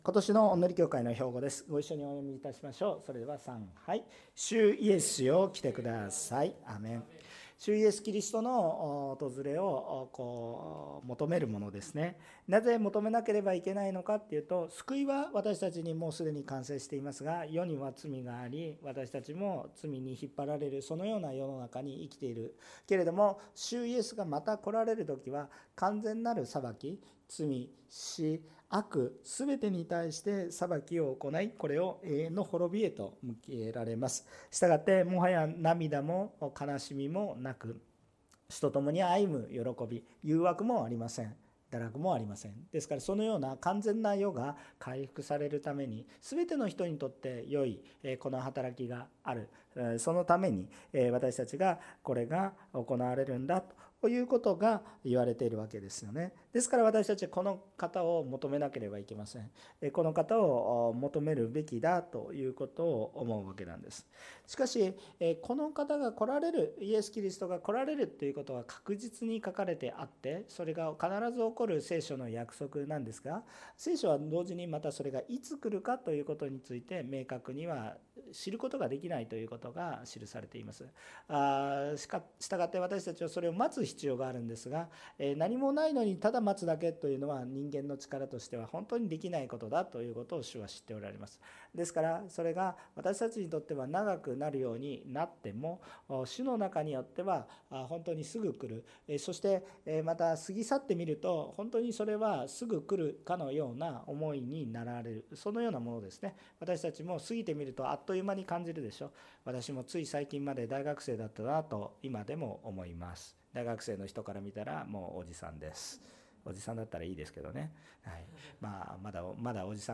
横浜オンヌリキリスト教会の説教を配信します。